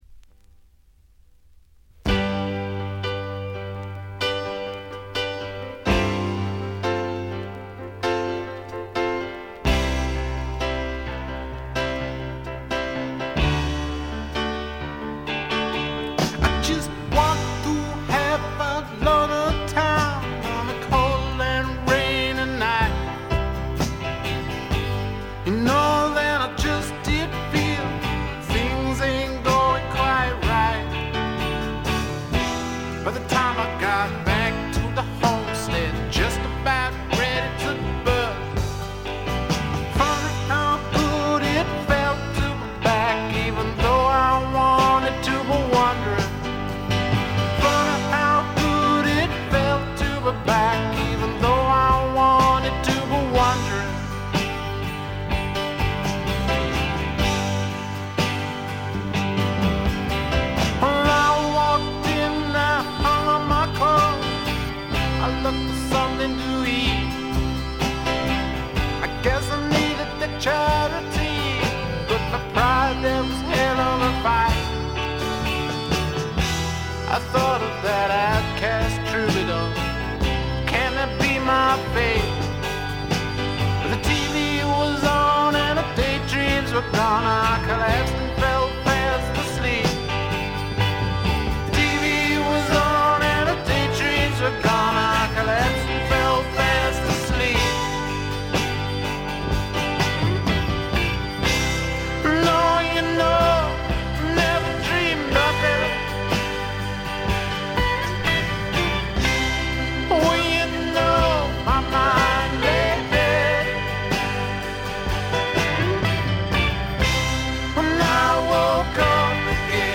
ごくわずかなノイズ感のみ。
ほのかない香るカントリー風味に、何よりも小粋でポップでごきげんなロックンロールが最高です！
試聴曲は現品からの取り込み音源です。
Rockfield Studios, South Wales